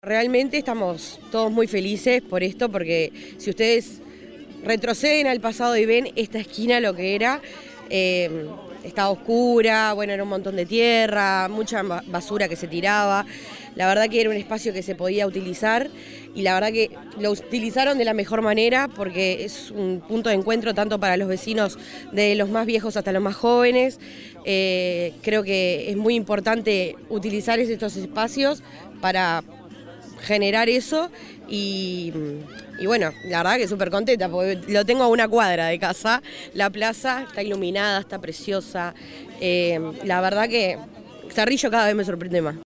vecina de la zona